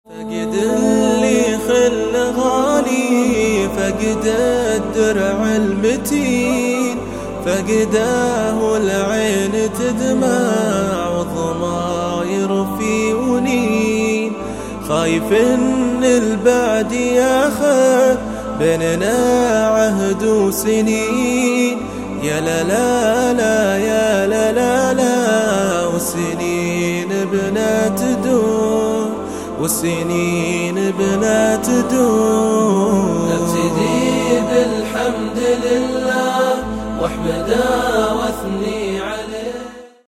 الأنواع: أناشيد